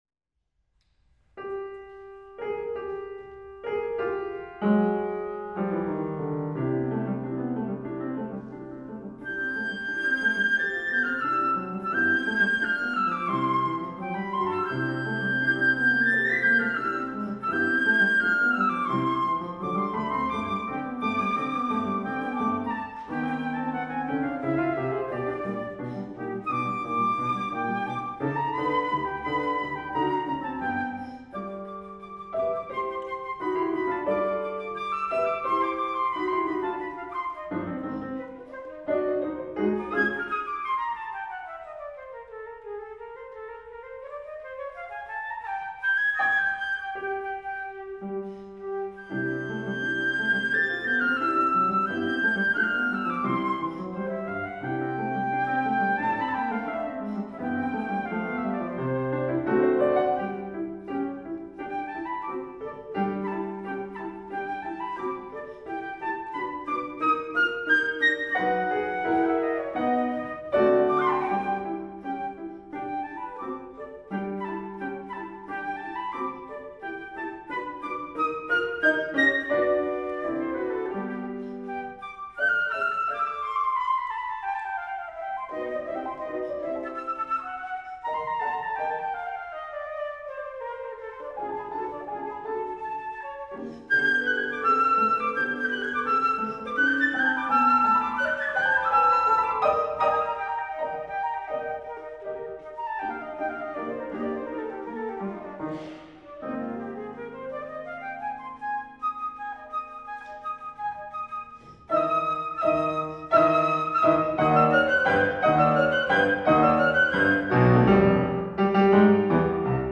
Master's flute recital